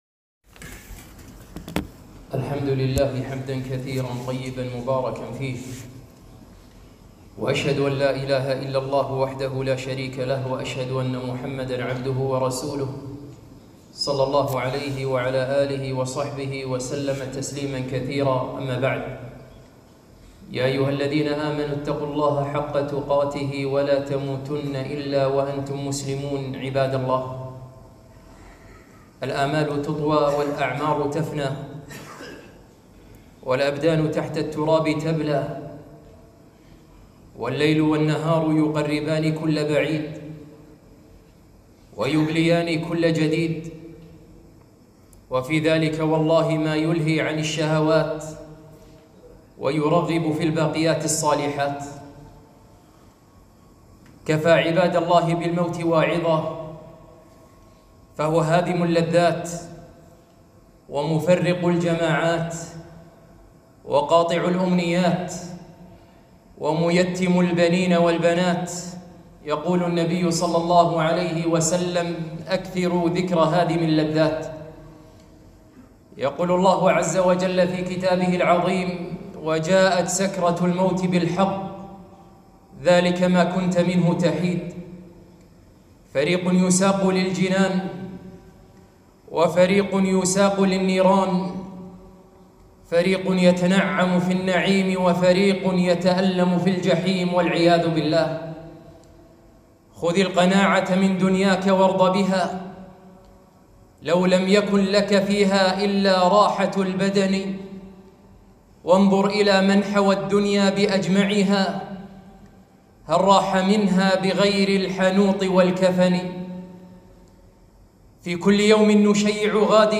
خطبة - إنه الموت